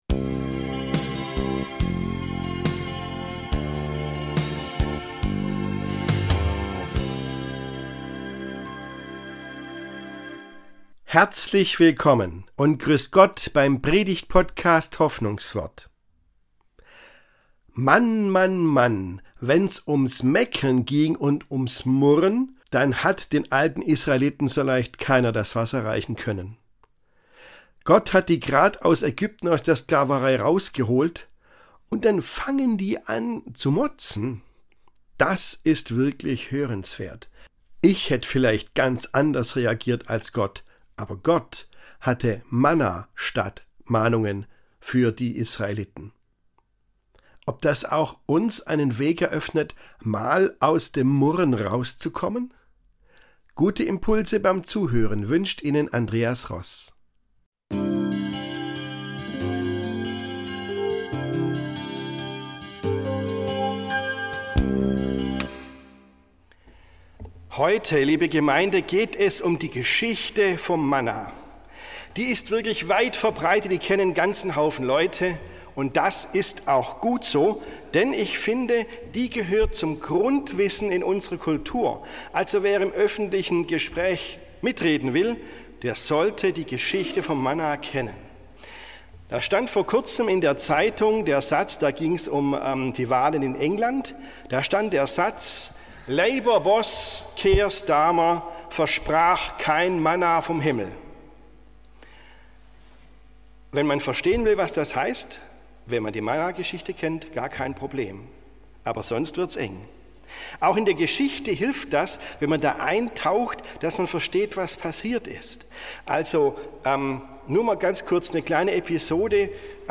Manna für Meckerköppe ~ Hoffnungswort - Predigten